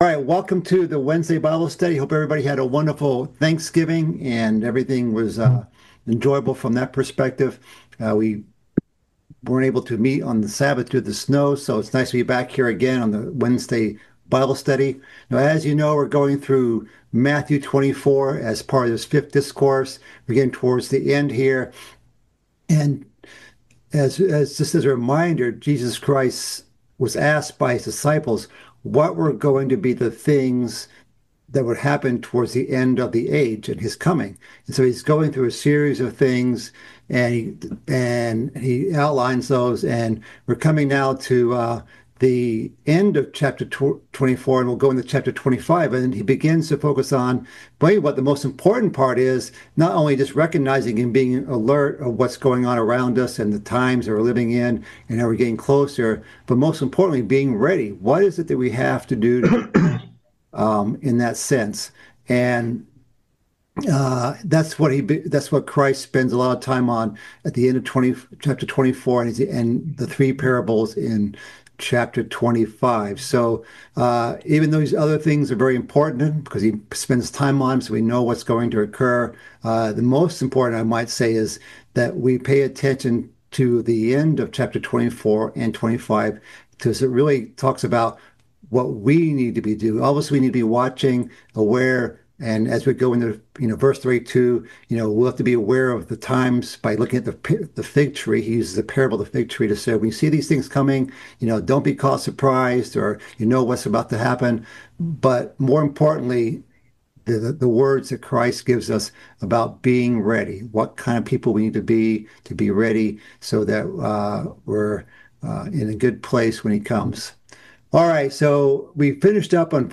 This is the tenth part of a mid-week Bible study series covering Christ's fifth discourse in the book of Matthew.